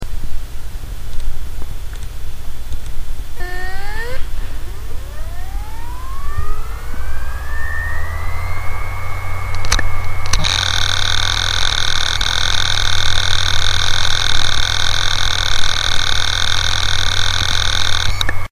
Все эти звуки были записаны с помощью японского контактного микрофона. К сожалению, полностью избавиться от фонового шума нам не удалось, да это и не надо: все звуки хорошо слышно, дополнительной очистки наши записи не требуют.
Звук накопителя Samsung 500GB с неисправным блоком магнитных головок: